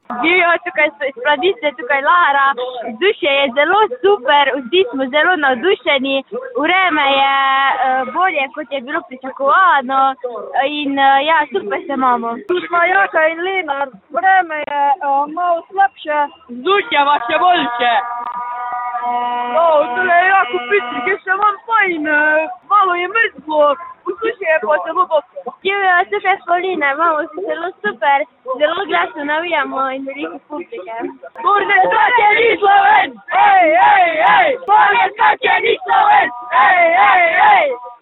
otroci.mp3